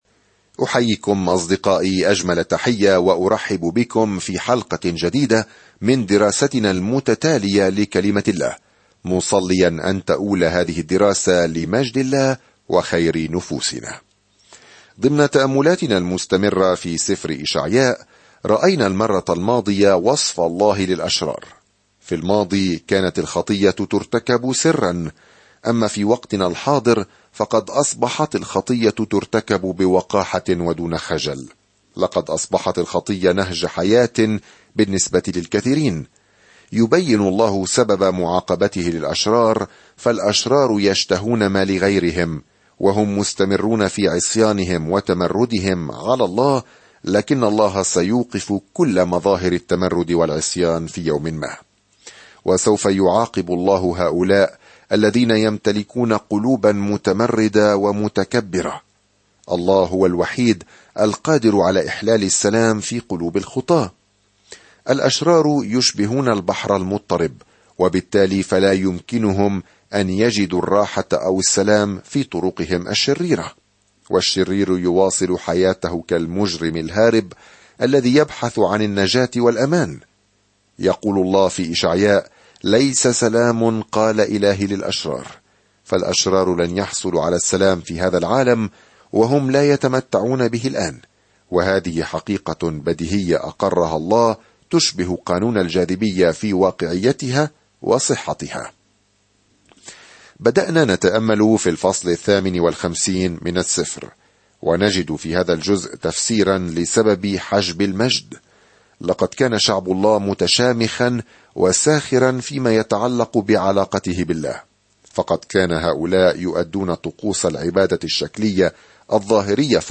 سافر يوميًا عبر إشعياء وأنت تستمع إلى الدراسة الصوتية وتقرأ آيات مختارة من كلمة الله.